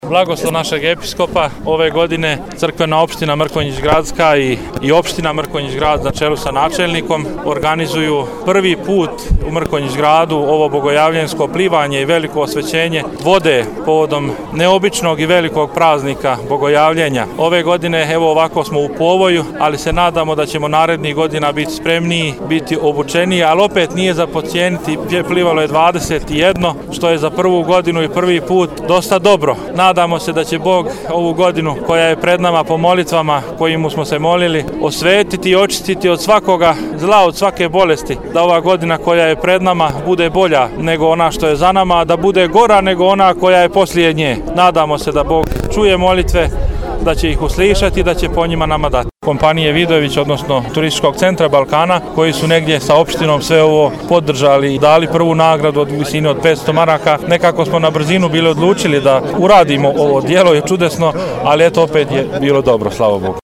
izjavu